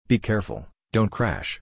crashen.mp3